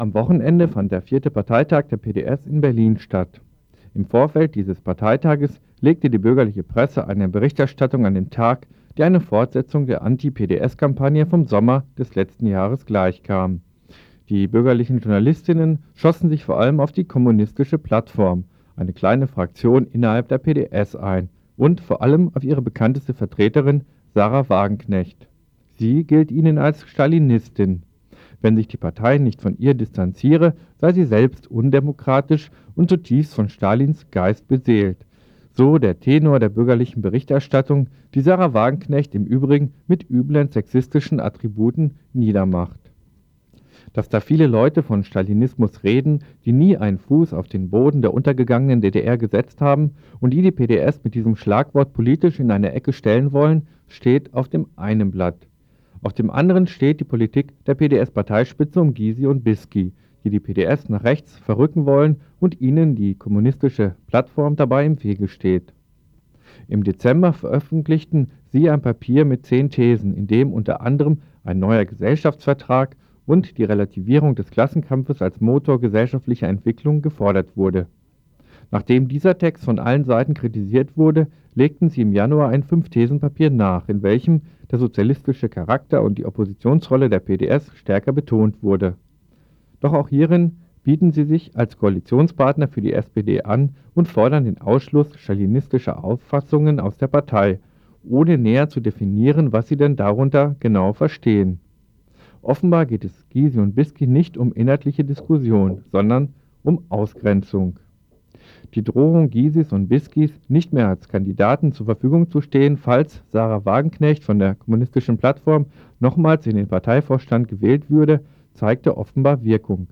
Bericht zum 4. Parteitag der PDS. Interview mit Mitglied der AG Junge GenossInnen der PDS zu Ergebnissen.